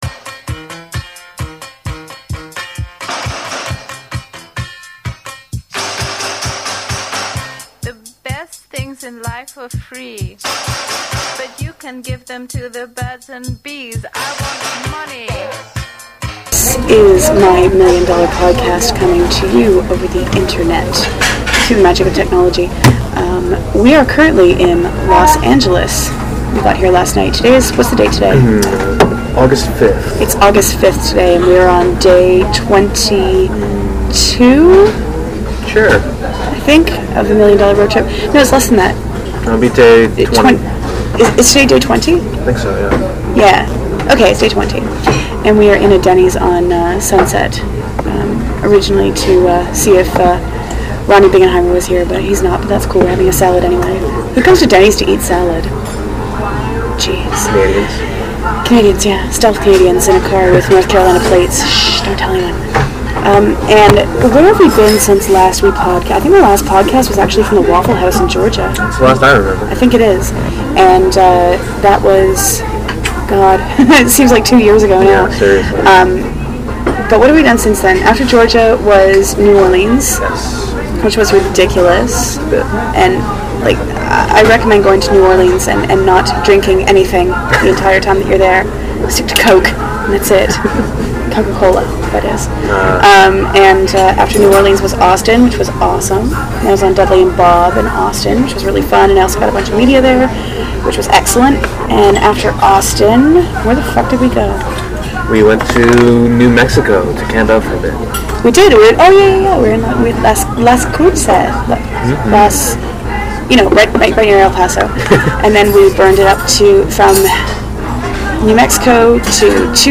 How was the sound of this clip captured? July 24, 2005 - My Million Dollar Podcast from Athens, Georgia!